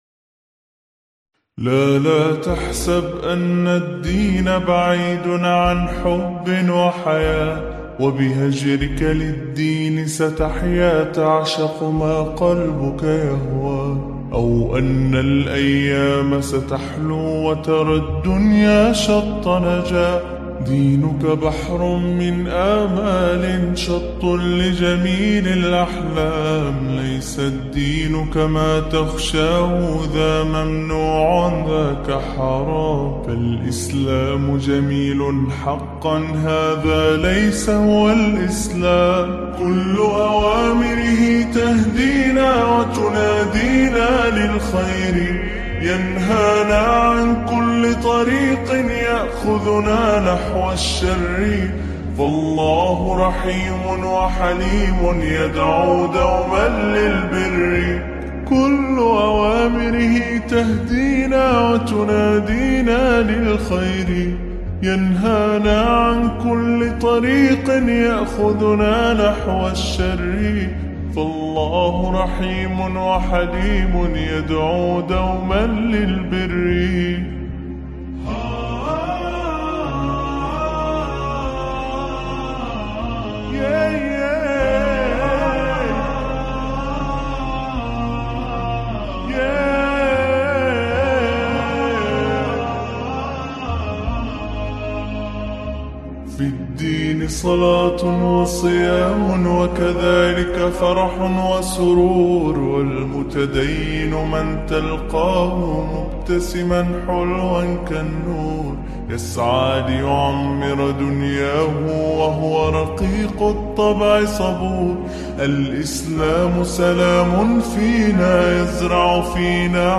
Enjoy this peaceful Islamic Nasheed.